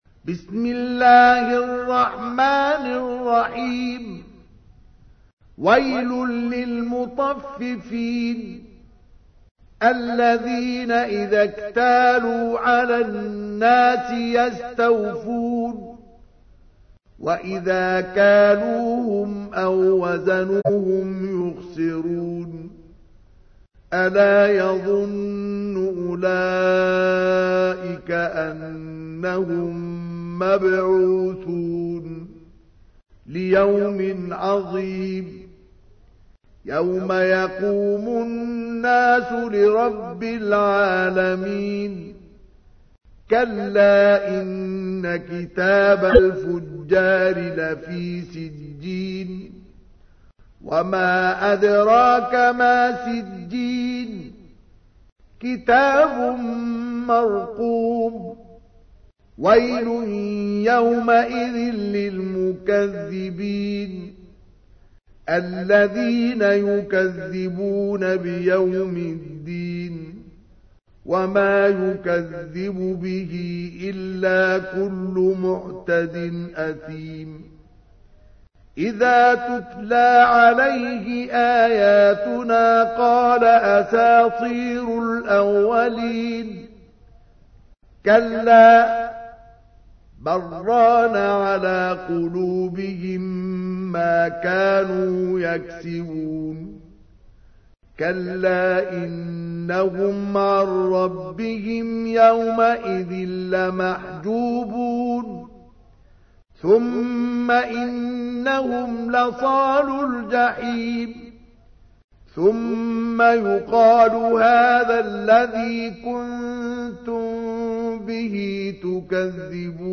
تحميل : 83. سورة المطففين / القارئ مصطفى اسماعيل / القرآن الكريم / موقع يا حسين